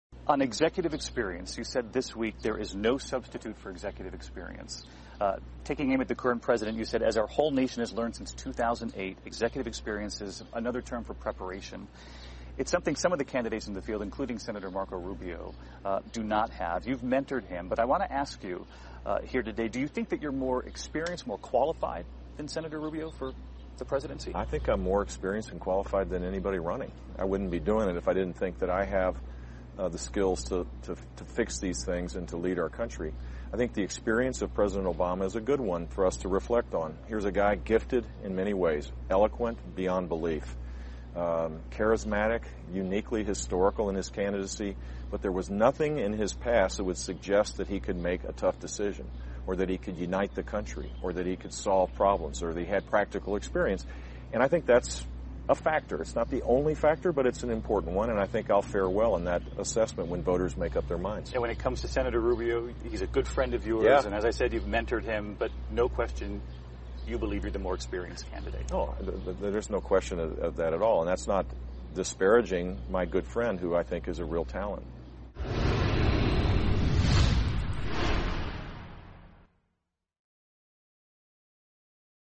访谈录 杰布·布什：我是美国总统最佳人选 听力文件下载—在线英语听力室